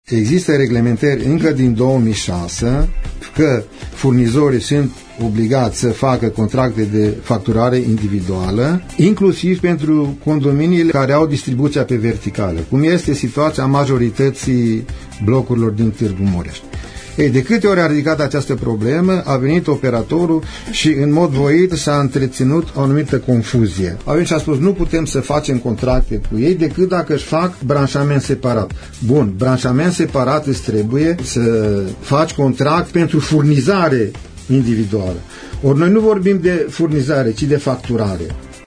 în emisiunea „Părerea Ta”